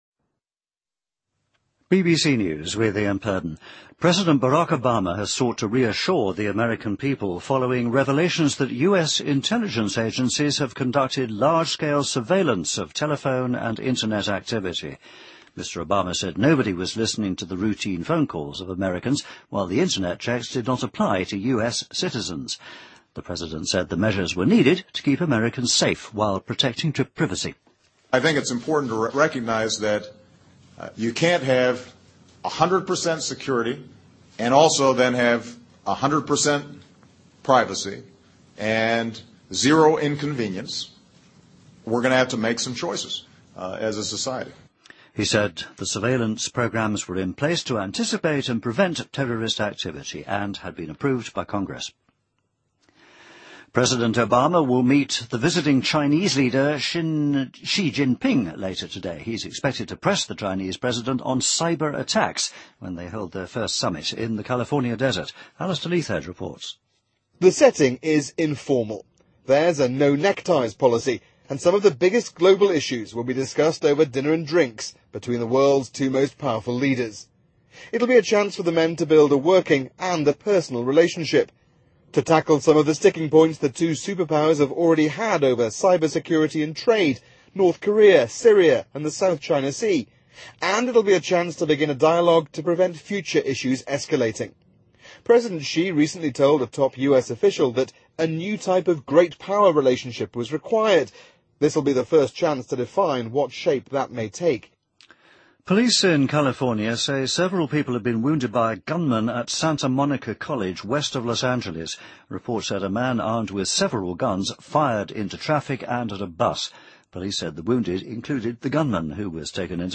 BBC news,2013-06-08